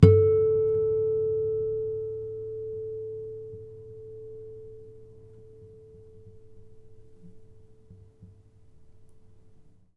harmonic-03.wav